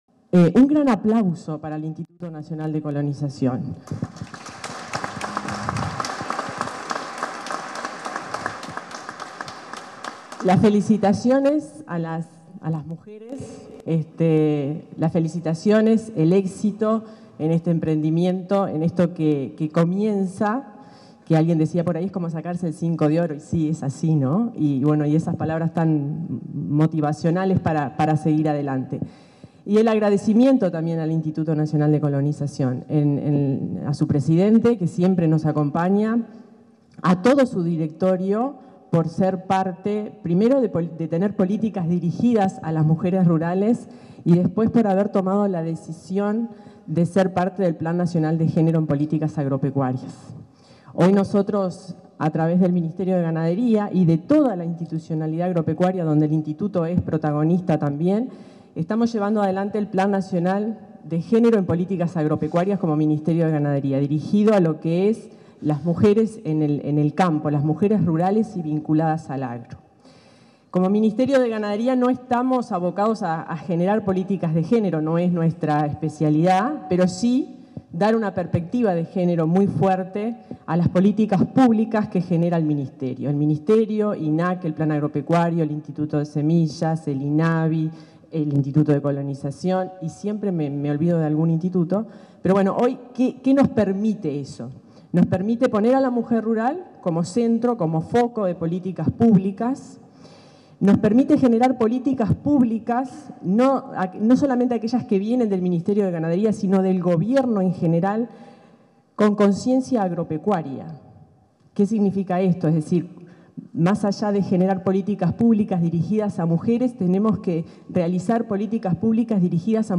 Conferencia de prensa por la adjudicación de campos a grupos de mujeres por parte de Colonización
Conferencia de prensa por la adjudicación de campos a grupos de mujeres por parte de Colonización 28/03/2023 Compartir Facebook X Copiar enlace WhatsApp LinkedIn En el marco de la ceremonia de adjudicación de campos a grupos de mujeres, este 28 de marzo, se expresaron la directora general del Ministerio de Ganadería, Agricultura y Pesca, Fernanda Maldonado, y el presidente del Instituto Nacional de Colonización, Julio César Cardozo.